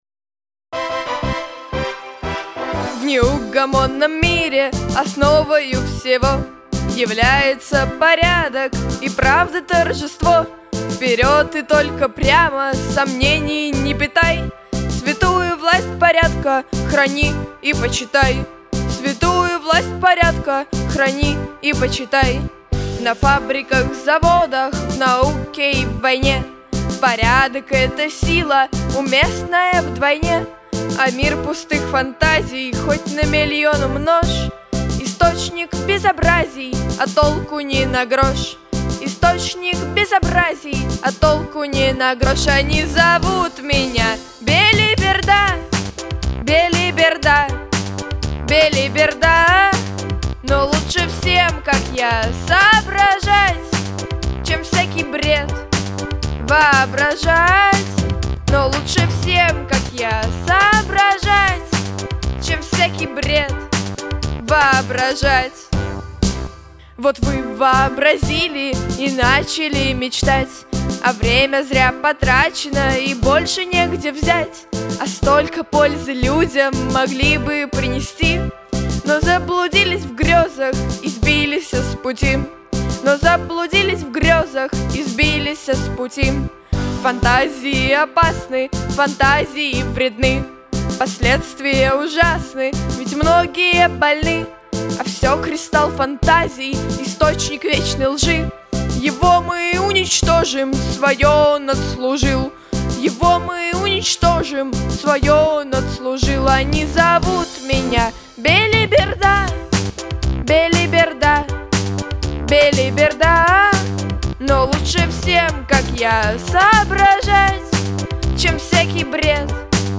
Записано в студии Easy Rider в октябре–декабре 2023 года